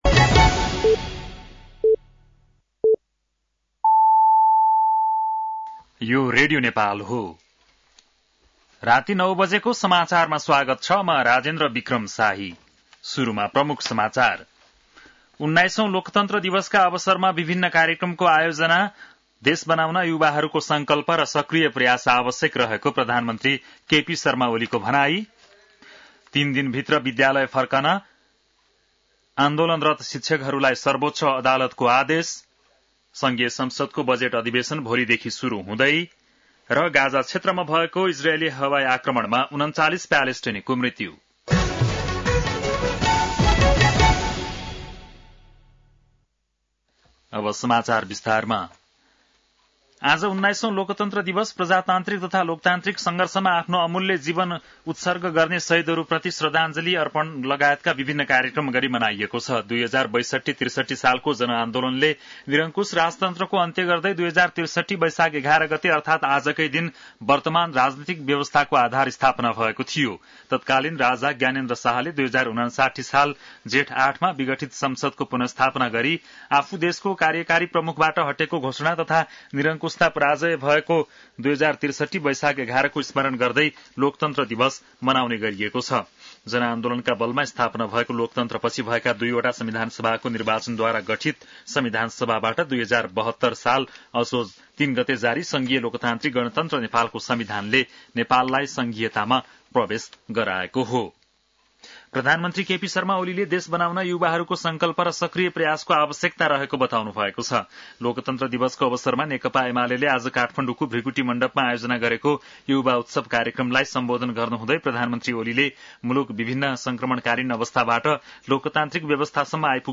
बेलुकी ९ बजेको नेपाली समाचार : ११ वैशाख , २०८२